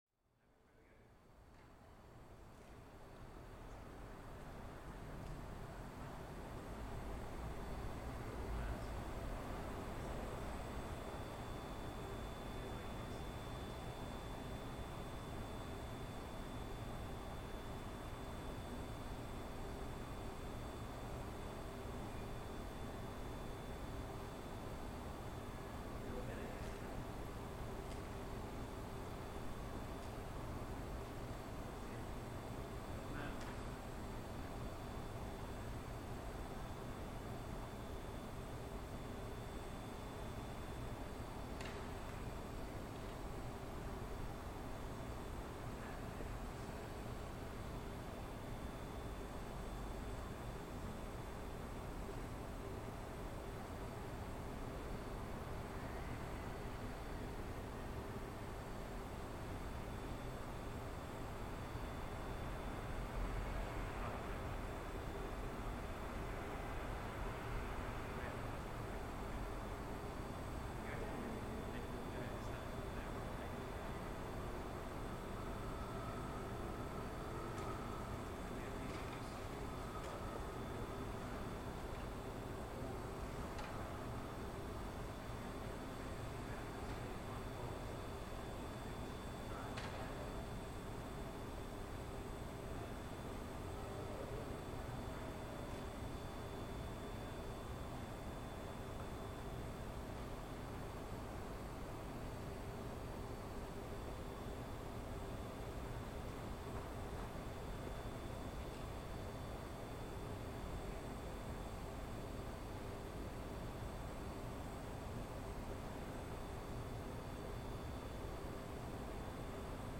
Oxford lockdown sound recorded by Cities and Memory.